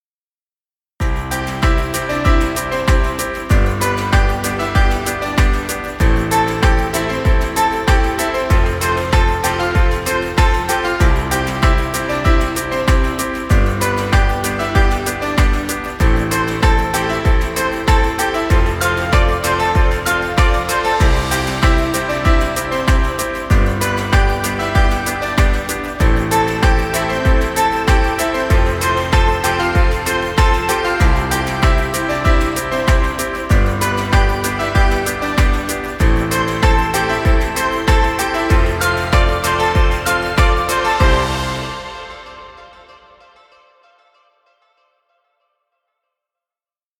Children music. Background music Royalty Free.